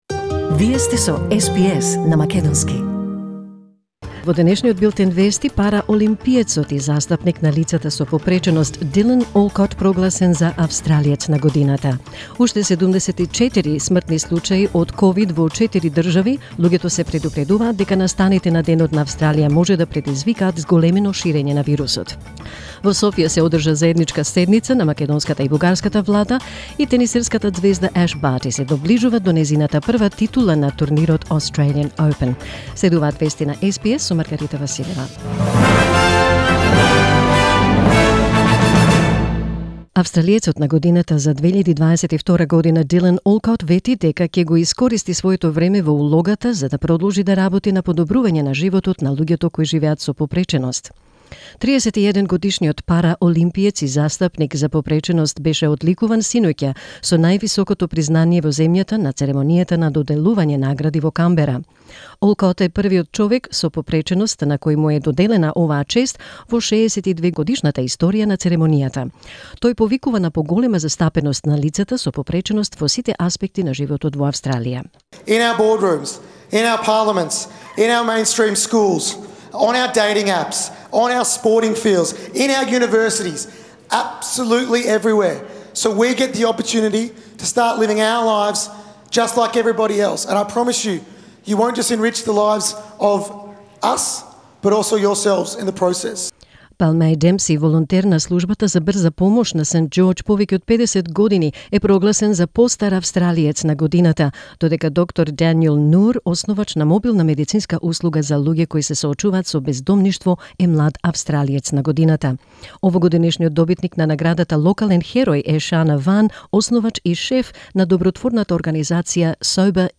SBS News in Macedonian 26 January 2022